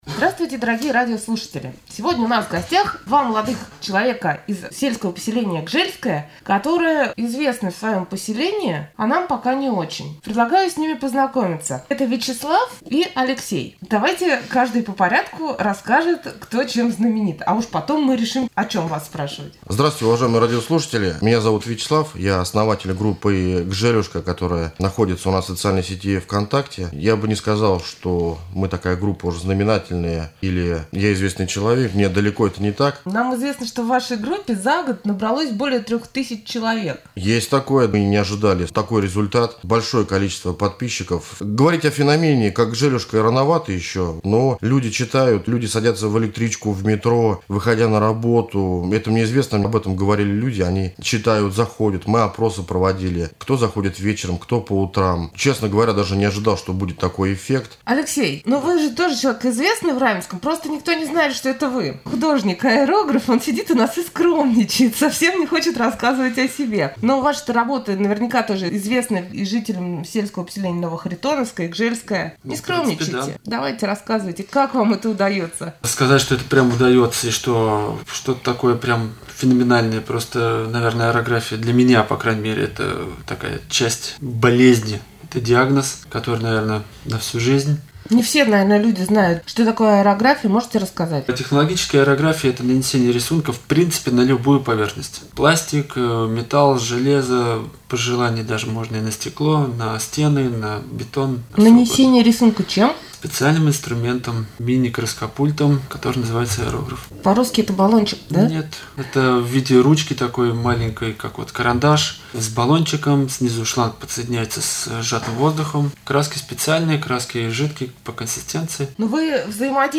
Беседу